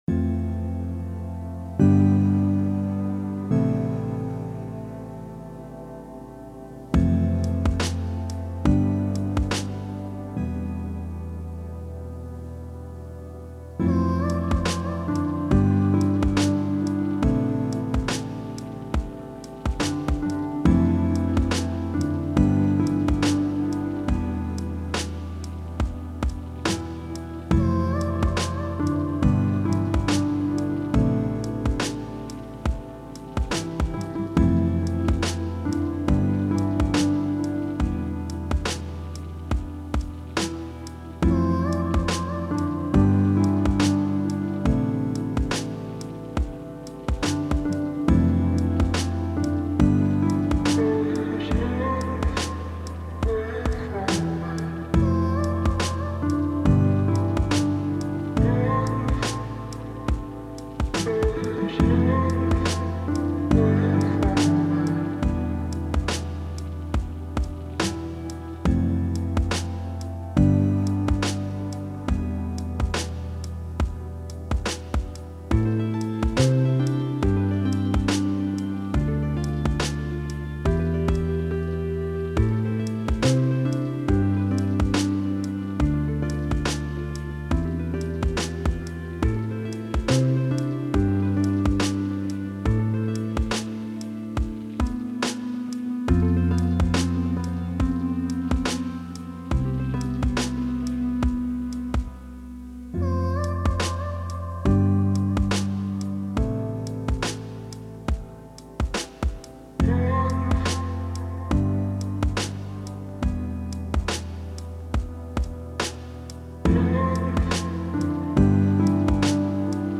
Home > Music > Beats > Bright > Smooth > Medium